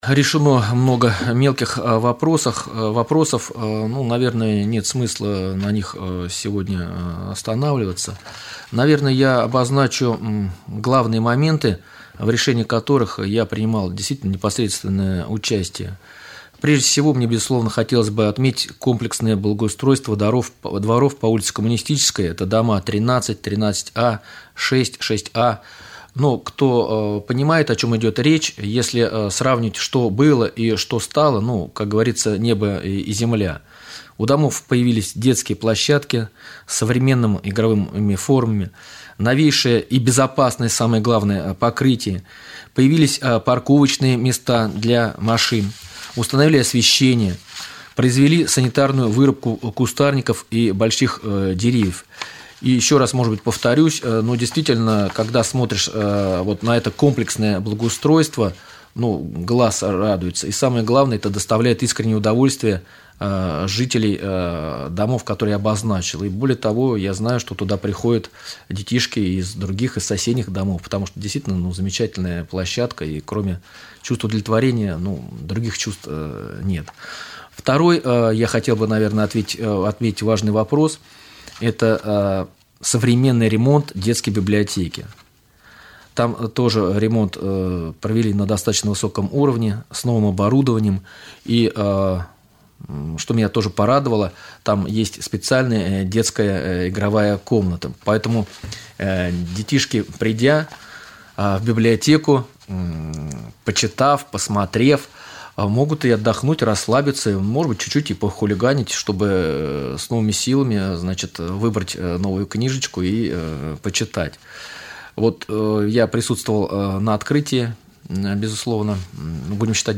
Об этом в прямом эфире Раменского радио рассказал депутат Совета депутатов городского поселения Раменское Алексей Демин.
Подробнее слушайте в интервью Алексея Демина